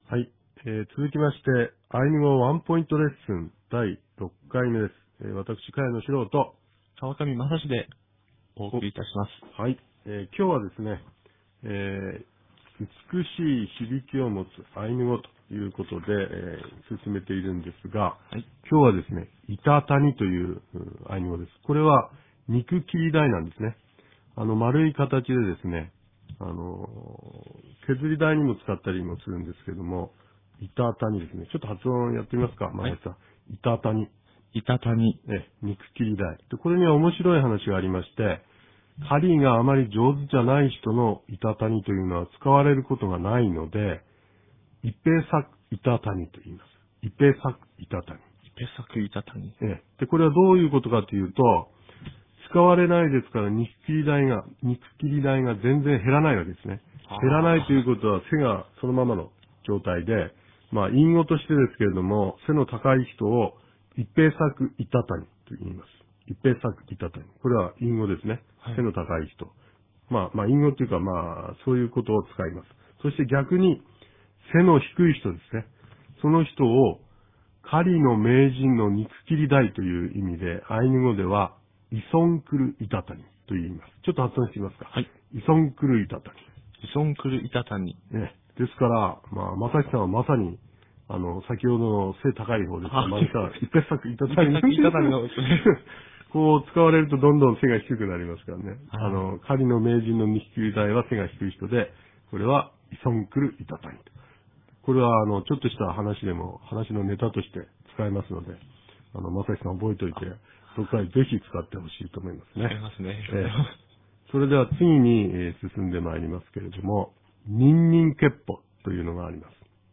■アイヌ語ワンポイントレッスン 第６回目